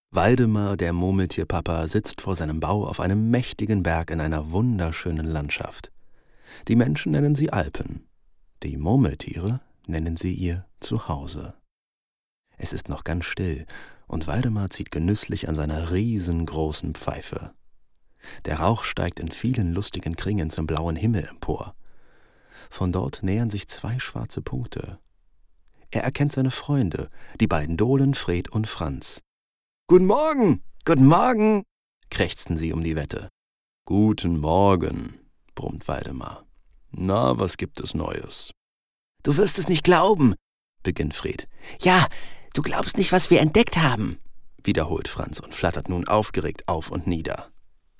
Werbung (Demo)
Commercial (Werbung), Station Voice